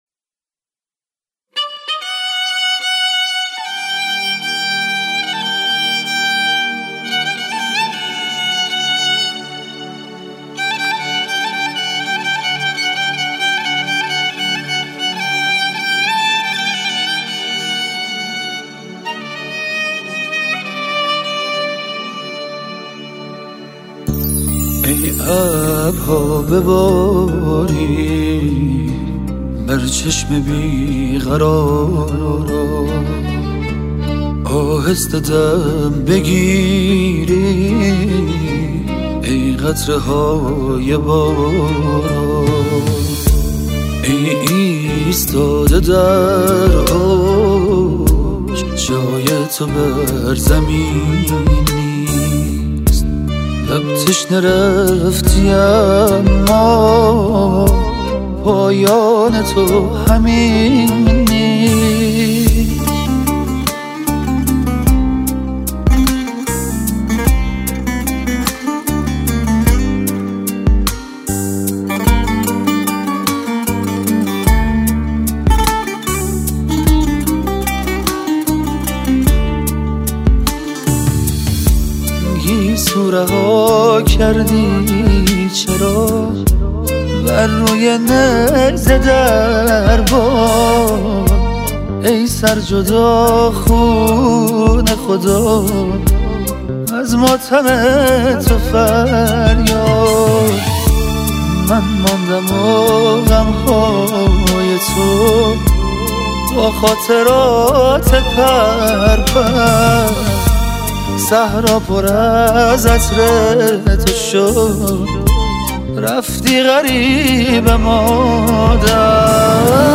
سرودهای امام حسین علیه السلام